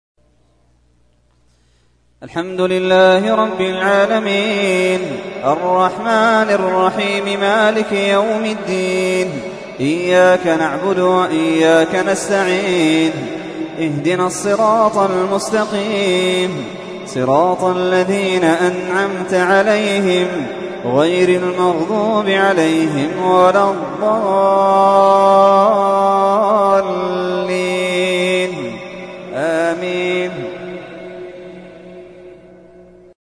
تحميل : 1. سورة الفاتحة / القارئ محمد اللحيدان / القرآن الكريم / موقع يا حسين